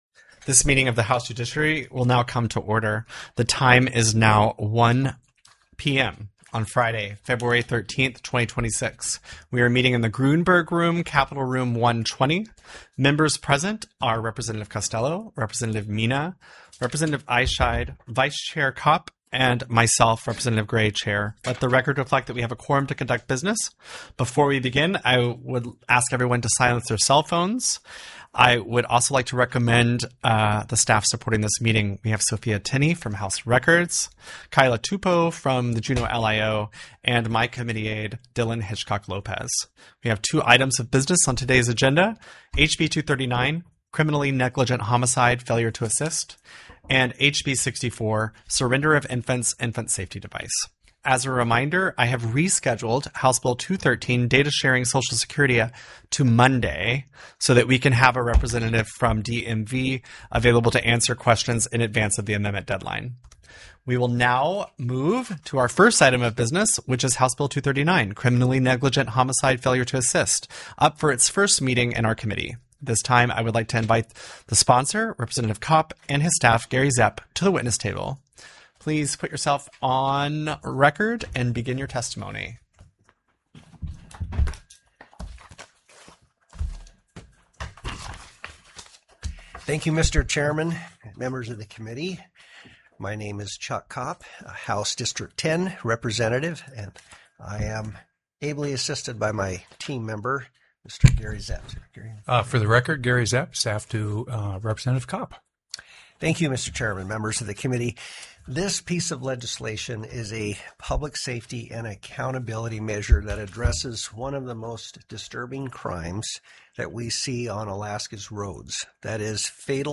The audio recordings are captured by our records offices as the official record of the meeting and will have more accurate timestamps.
HB 239 CRIM. NEG. HOMICIDE; FAILURE TO ASSIST TELECONFERENCED Heard & Held -- Public Testimony -- += HB 64 SURRENDER OF INFANTS; INF.